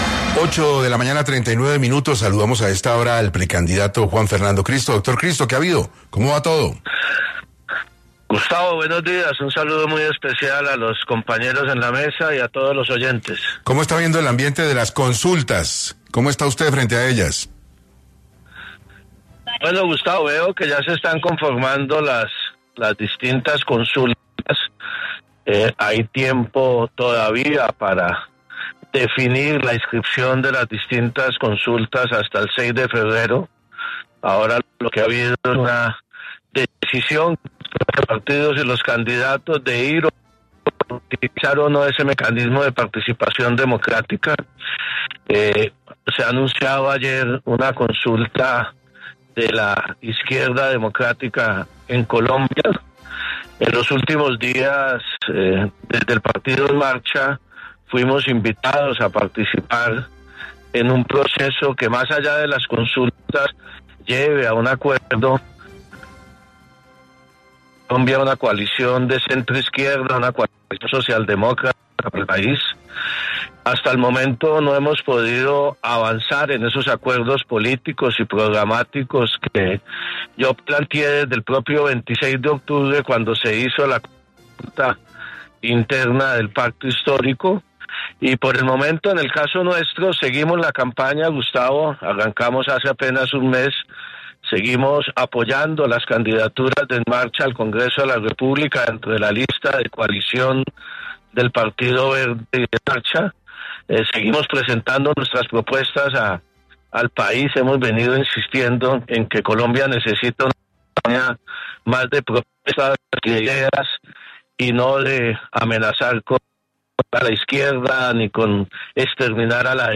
El exministro y ahora precandidato presidencial, Juan Fernando Cristo, estuvo en 6 AM de Caracol Radio, hablando acerca de las diferentes consultas que se han consolidado en Colombia de cara a las elecciones presidenciales del 2026, al igual que su posición como precandidato frente a dichas coaliciones.